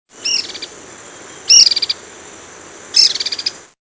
Conopias albovittatus (white-ringed flycatcher)
Fortuna Falls, near La Fortuna (Costa Rica)
A white-ringed flycatcher (Conopias albovittatus) calls in the early evening.